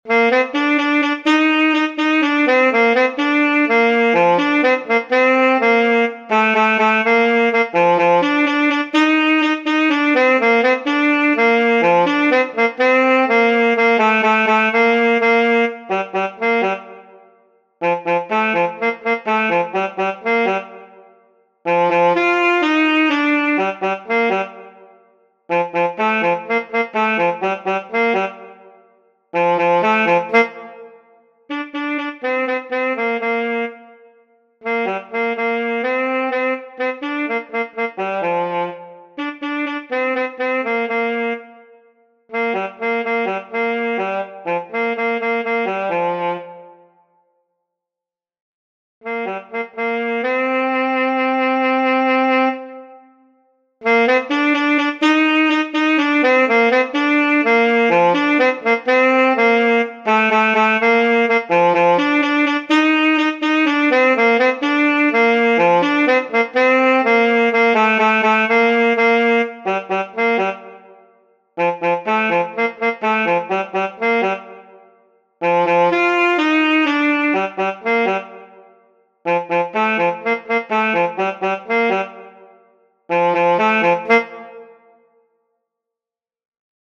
saksofon alt solo